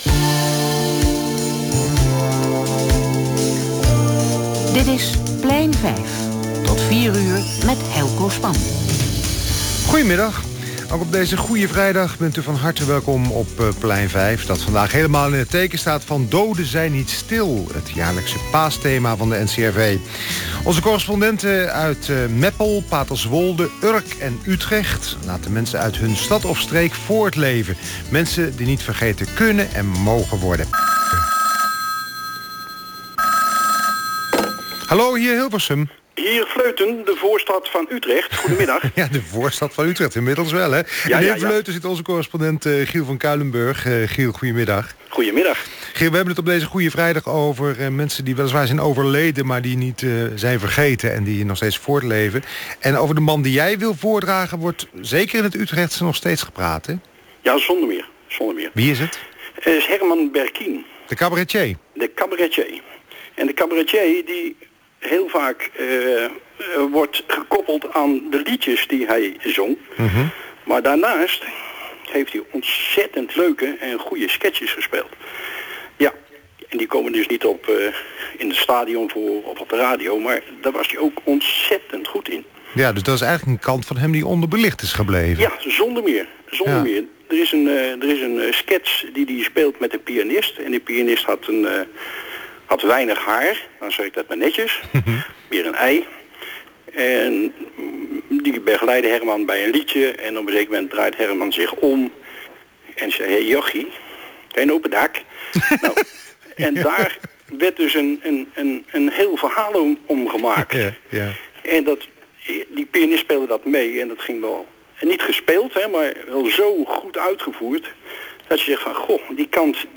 Beluister hier het interview: Plein 5 (NCRV) op Radio V